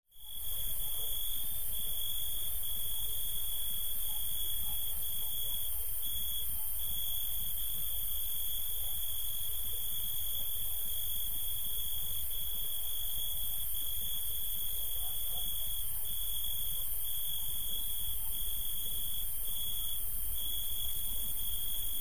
На этой странице собраны звуки цикад — от монотонного стрекотания до интенсивного хора насекомых.
Шепот ночного хора сверчков и цикад